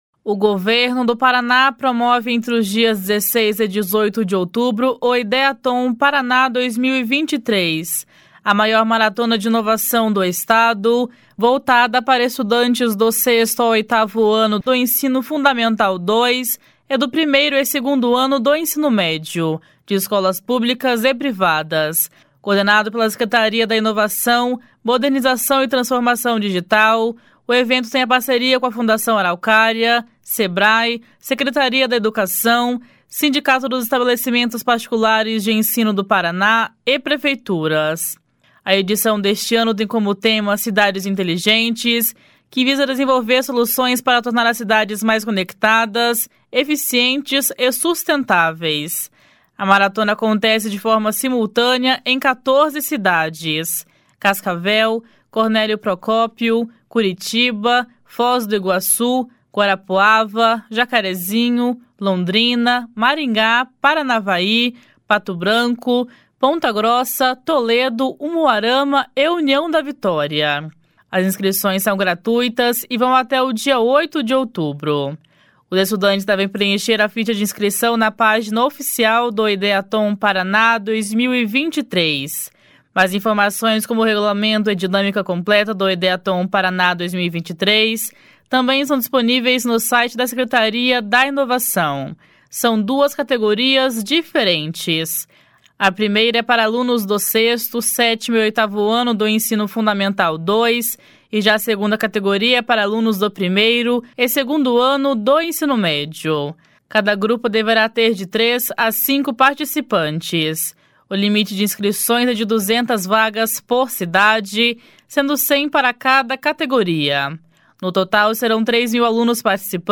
// SONORA MARCELO RANGEL //